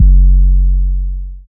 Inside The Mattress 808.wav